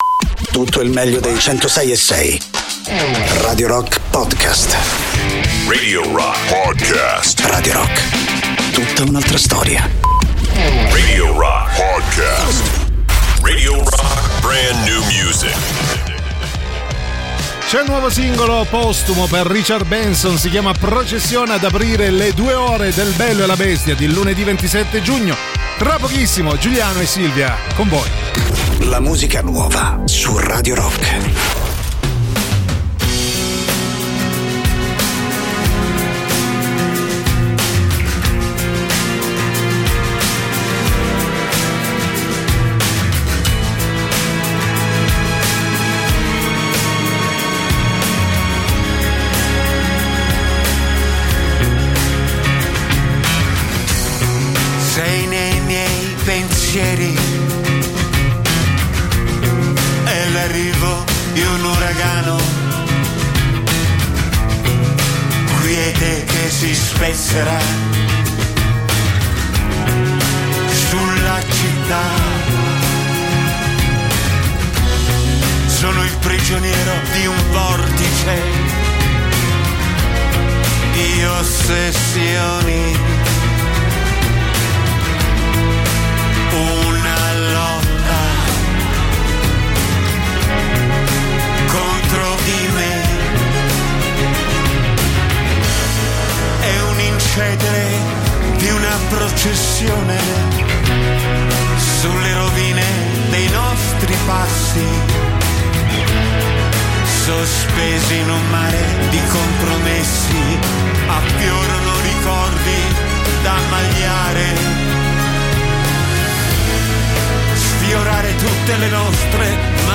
in diretta sui 106.6 di Radio Rock dal Lunedì al Venerdì dalle 13.00 alle 15.00.